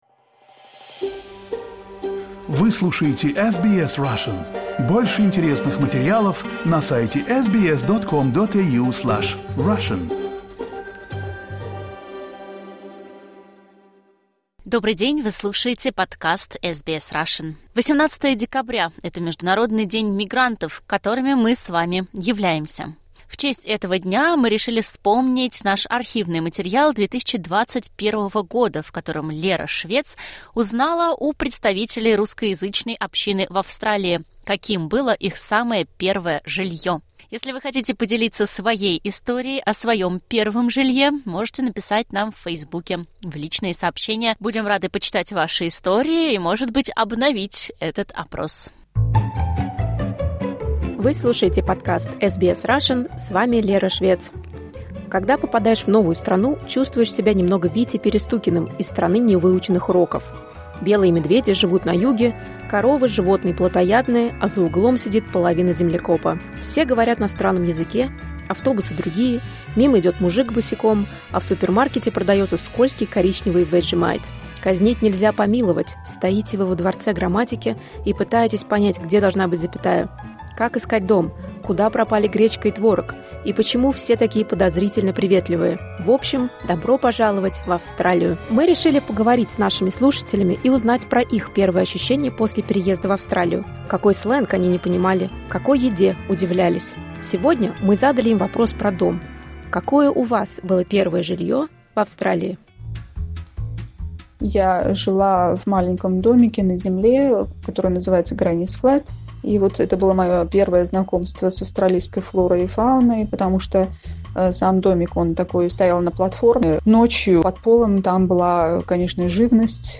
Опрос: Австралийское и советское детство